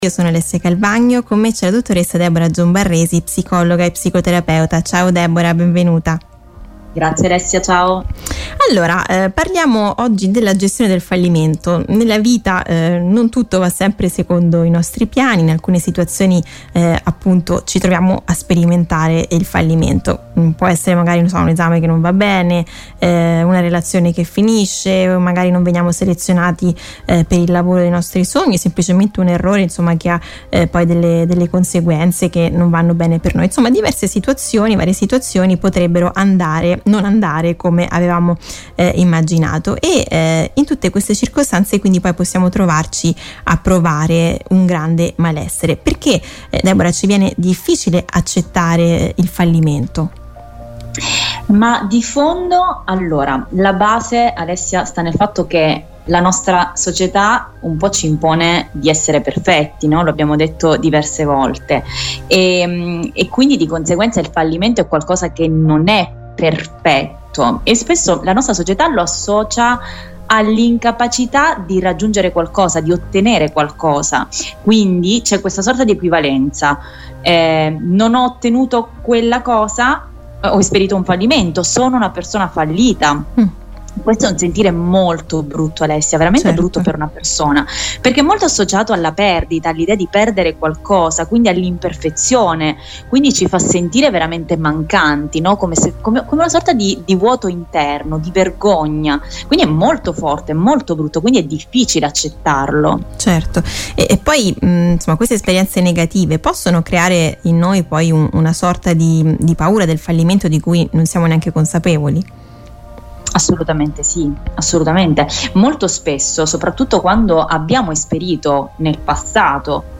psicologa e psicoterapeuta.